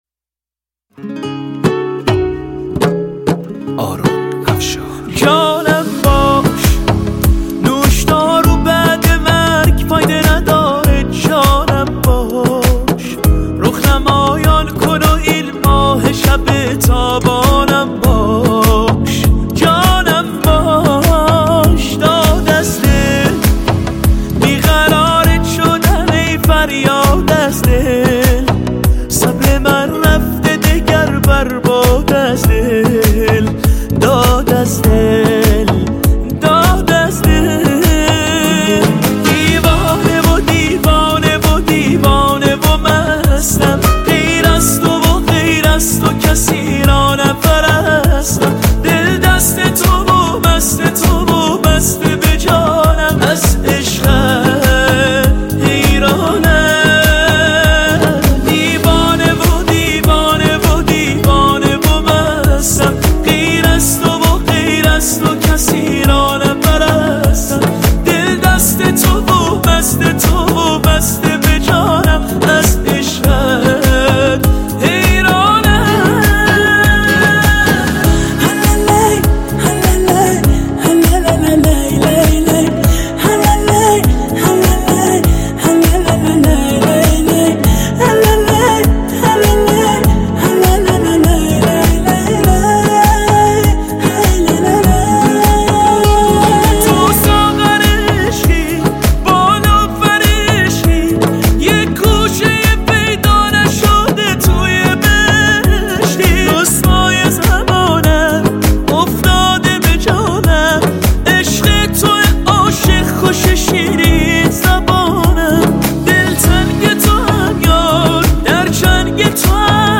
سبک : موسیقی پاپ